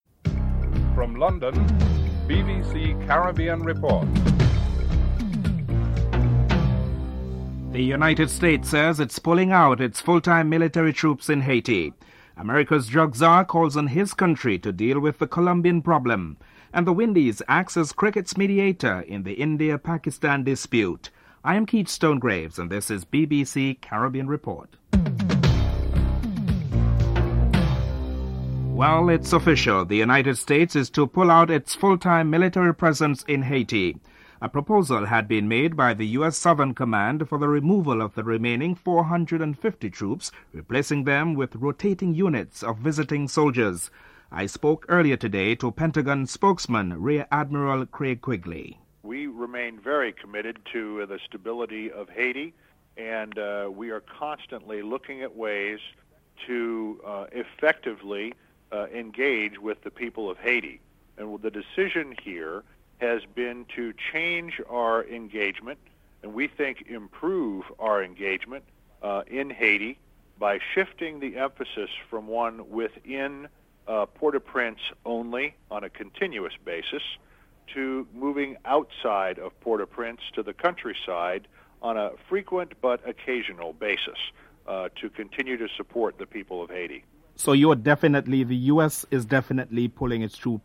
1. Headlines